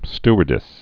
(stər-dĭs, sty-)